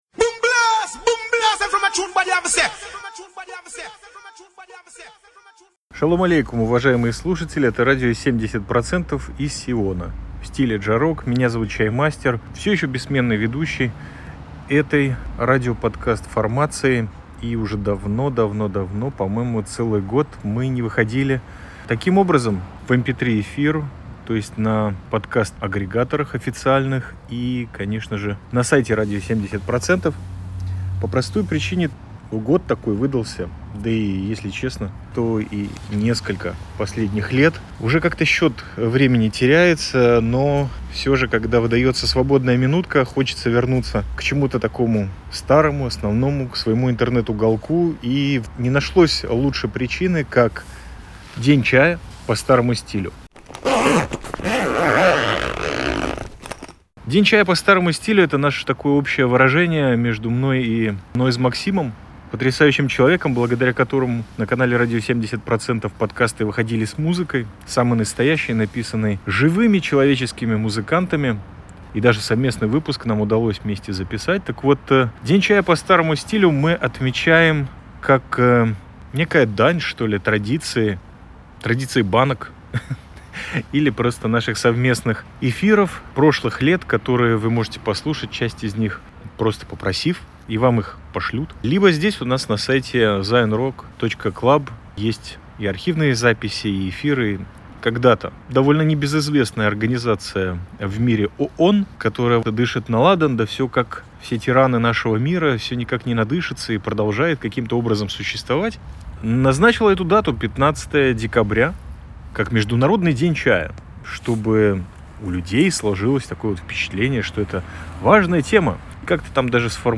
Это 1-ый стриткаст Радио 70% за последний долгий год. пара десятков слов о первой дате Международного дня чая.